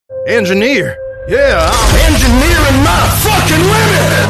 Engineer-yeah-im-engi-nearing-my-limit-1.mp3